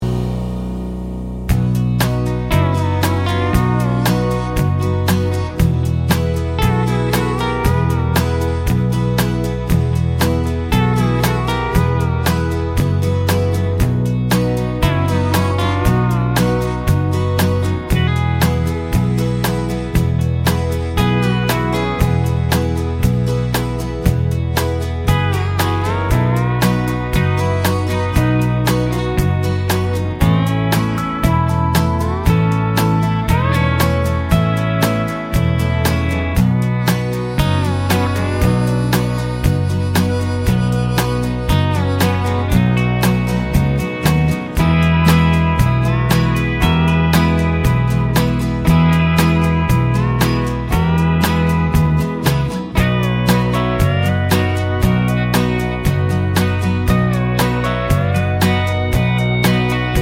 no Backing Vocals Country (Female) 3:24 Buy £1.50